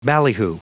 Prononciation du mot ballyhoo en anglais (fichier audio)
Prononciation du mot : ballyhoo